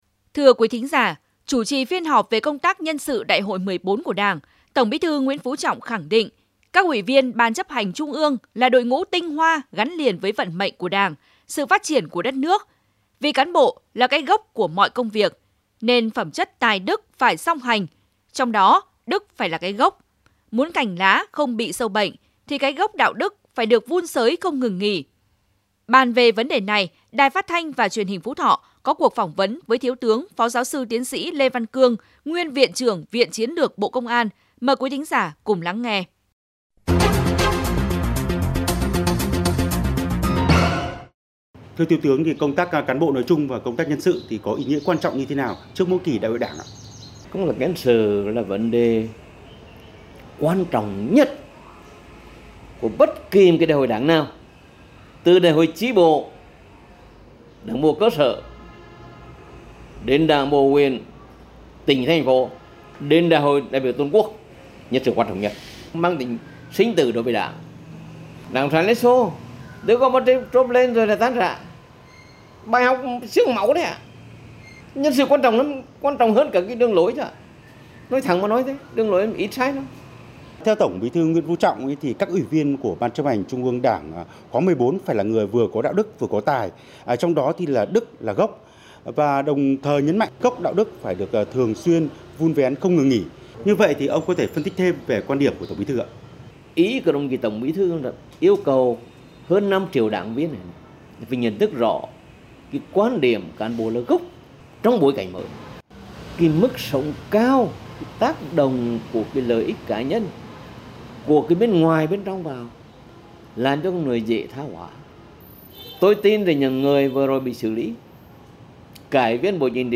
57658_PHUTHO_PHONGVAN_VUN_GOC_DE_TRANH_LUI_CANH.mp3